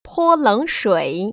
[pō lěngshuĭ] 포렁수이